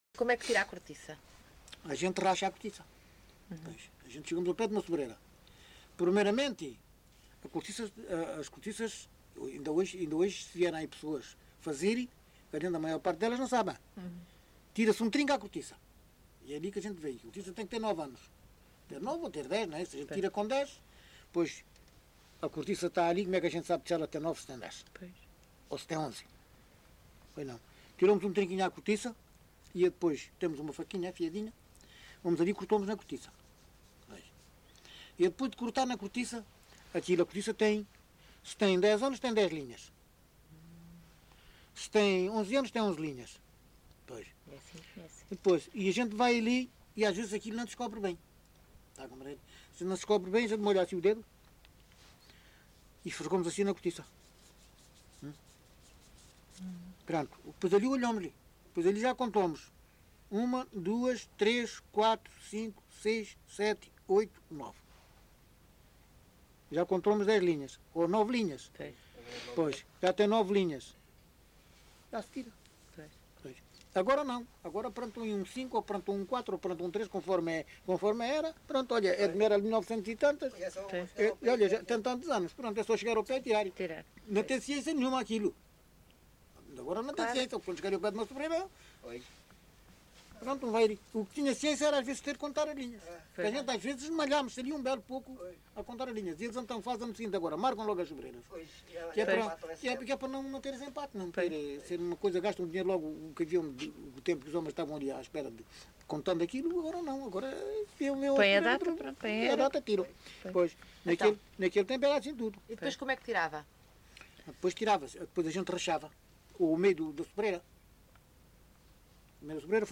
LocalidadeVale Chaim de Baixo (Odemira, Beja)